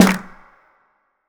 HFMSnare2.wav